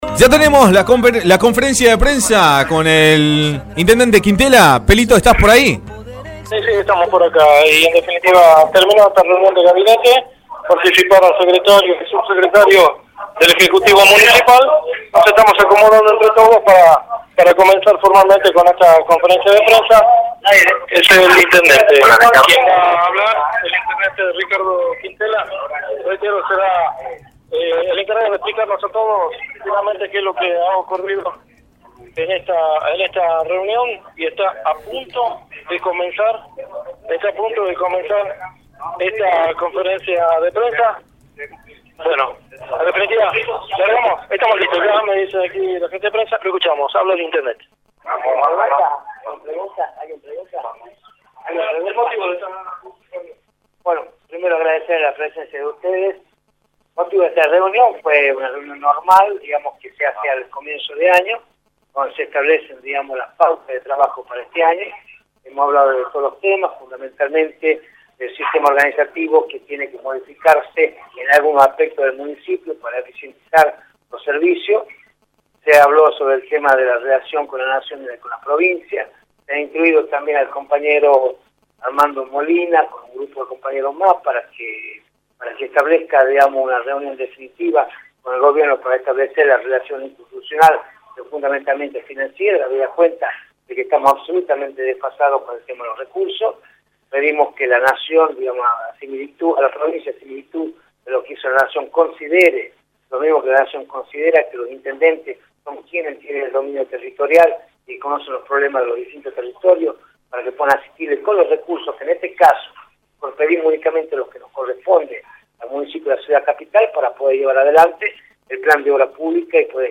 Ricardo Quintela, intendente capitalino, por Radio Rioja
06-conferencia-de-prensa-intendente.mp3